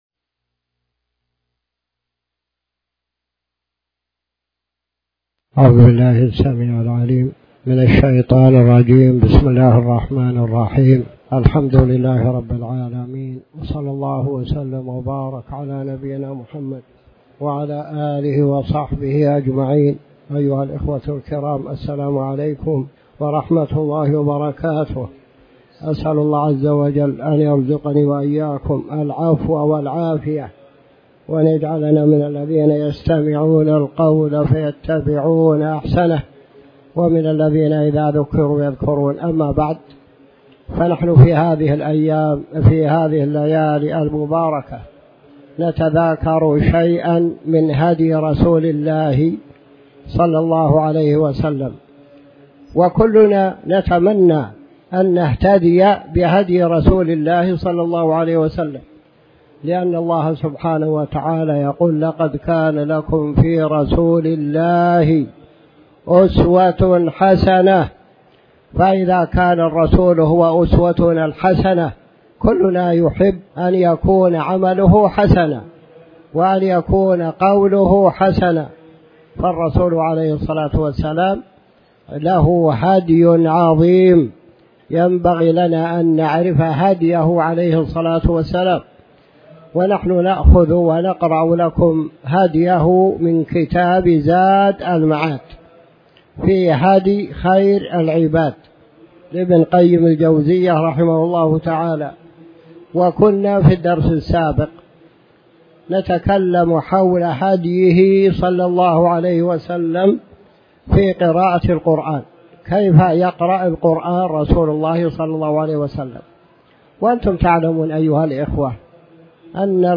تاريخ النشر ٥ محرم ١٤٤٠ هـ المكان: المسجد الحرام الشيخ